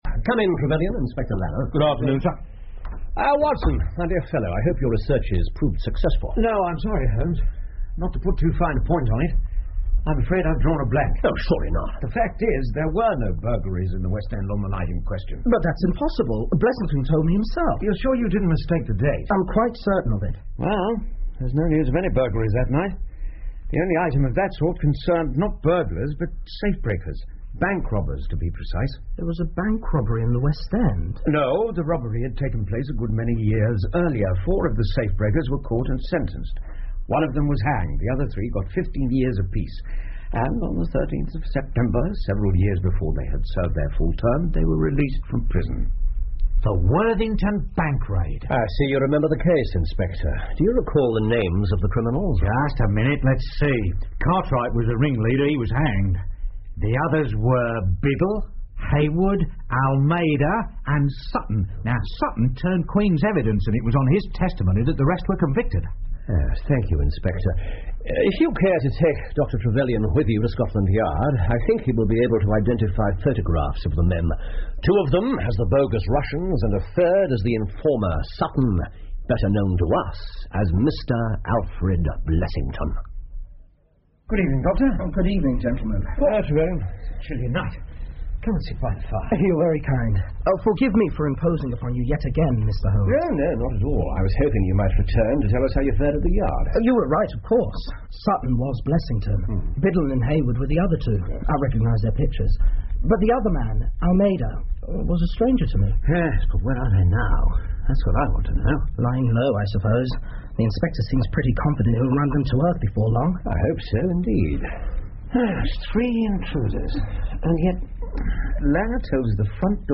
福尔摩斯广播剧 The Resident Patient 8 听力文件下载—在线英语听力室
在线英语听力室福尔摩斯广播剧 The Resident Patient 8的听力文件下载,英语有声读物,英文广播剧-在线英语听力室